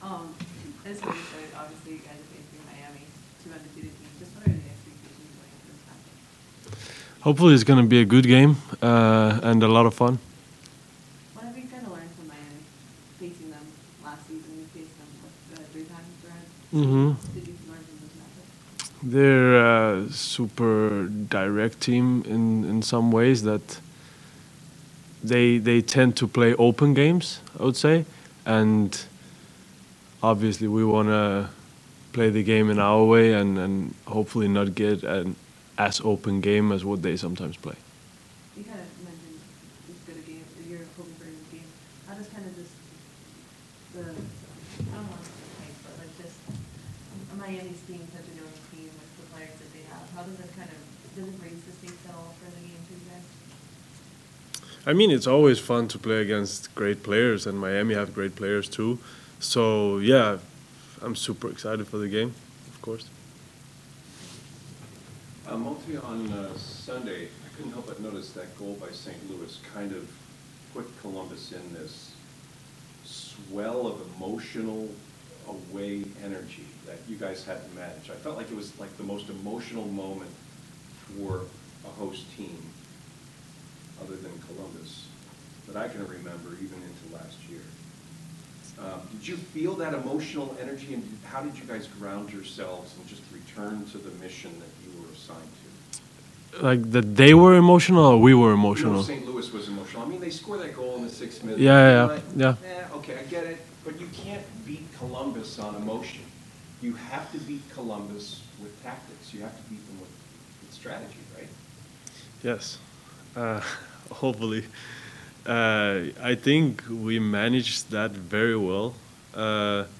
Malte Amundsen previewing match against Inter Miami CF (courtesy Crew Communications)